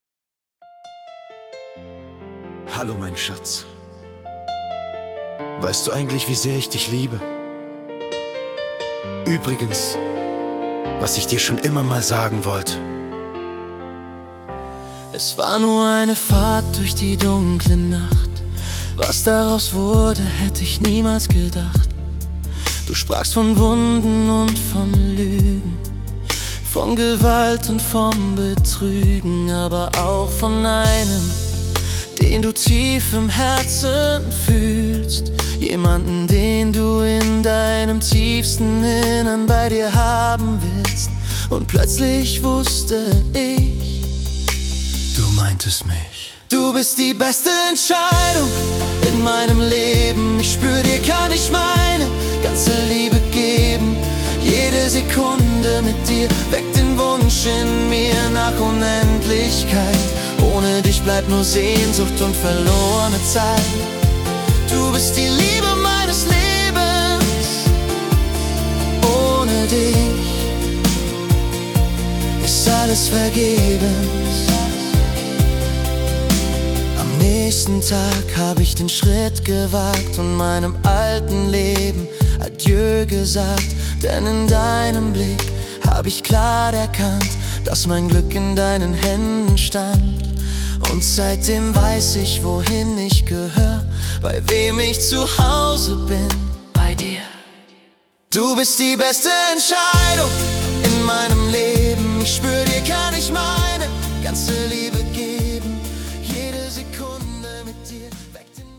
(Songwriter-Ballade)